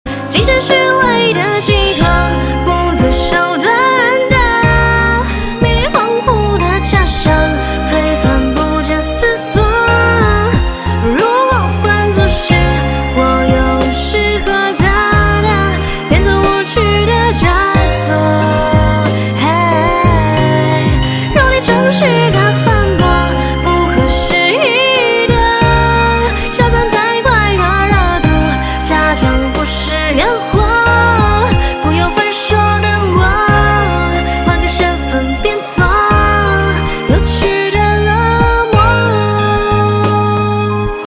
M4R铃声, MP3铃声, 华语歌曲 102 首发日期：2018-05-13 17:07 星期日